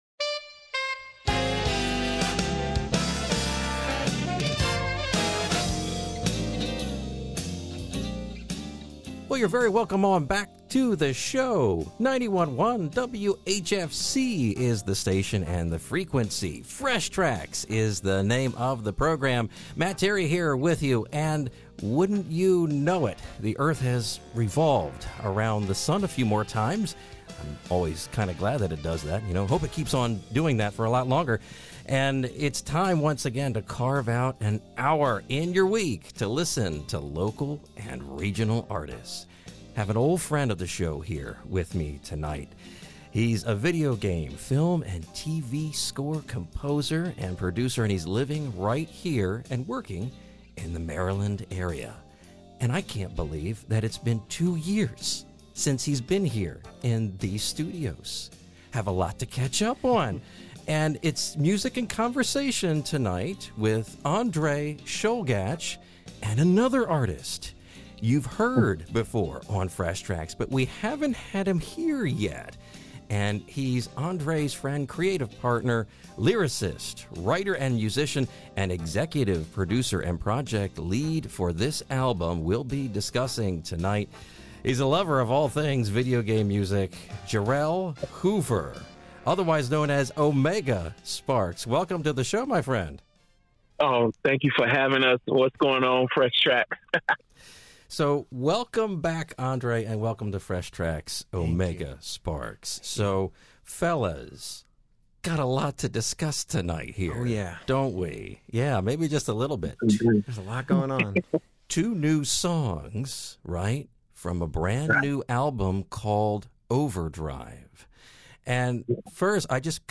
91.1 WHFC Exclusive Radio Interview - Overdrive Album